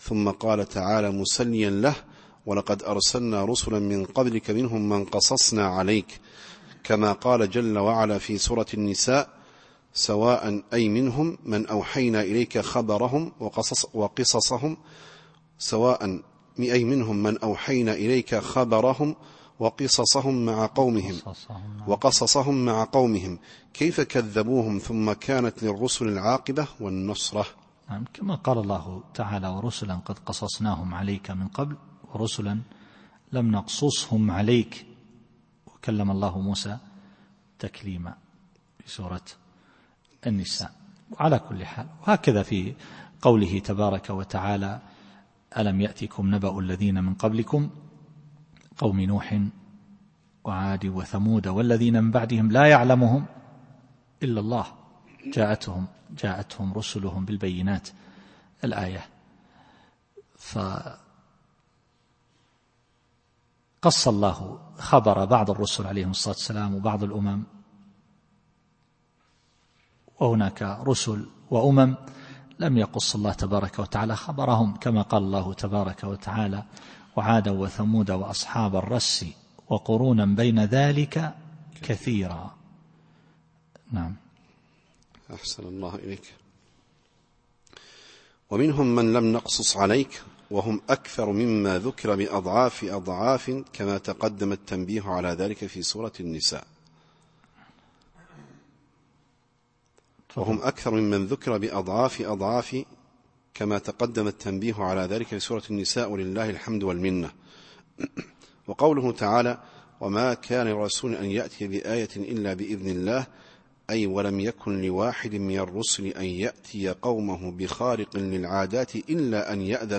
التفسير الصوتي [غافر / 78]